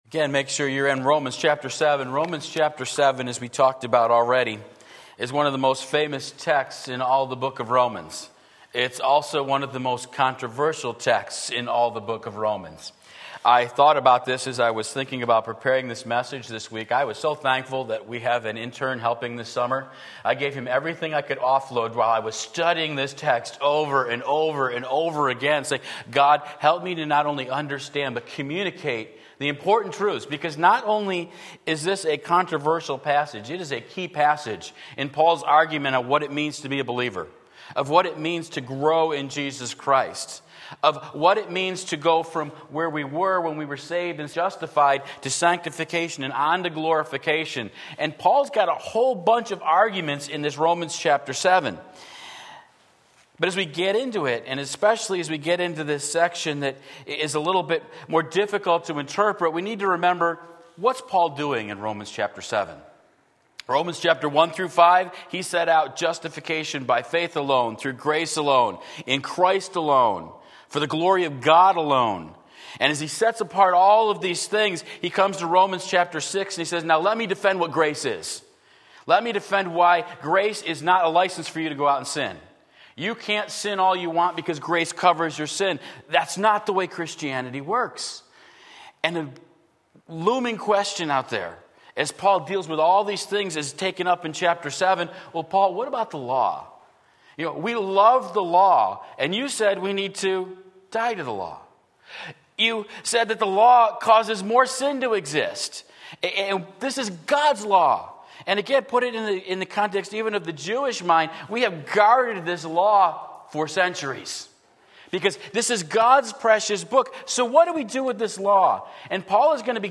Sermon Link
War in My Members Romans 7:14-16 Sunday Morning Service